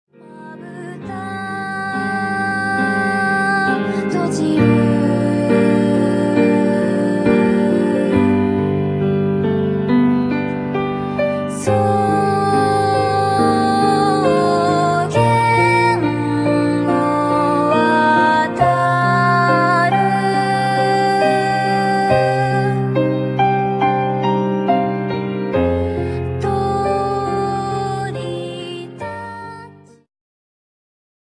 ジャンル Progressive
アコースティック
バイオリンフューチュア
ボーカルフューチュア
癒し系
「どこにもない世界」を彷徨う凛としたピアノとヴォイスの女性デュオ。